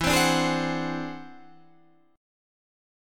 E+9 Chord
Listen to E+9 strummed